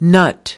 1. Nut  /nʌt/ : quả hạch